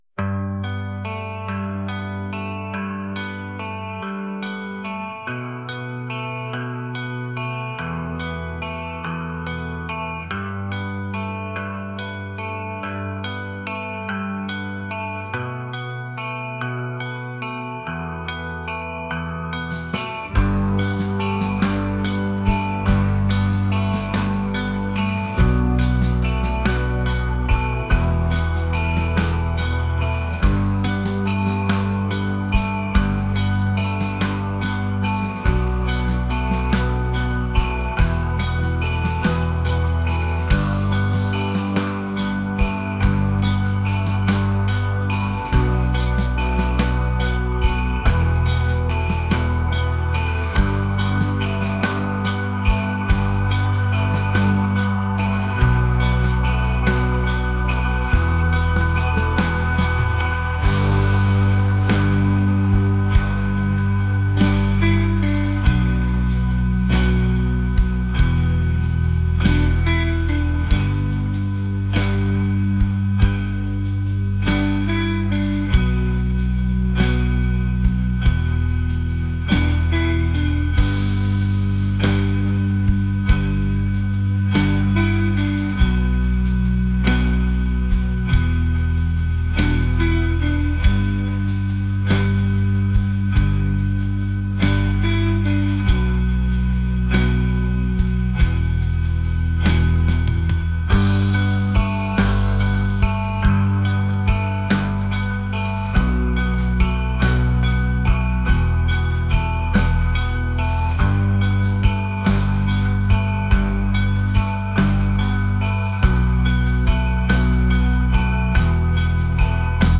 indie | pop | ambient